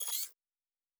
pgs/Assets/Audio/Sci-Fi Sounds/Weapons/Additional Weapon Sounds 3_5.wav
Additional Weapon Sounds 3_5.wav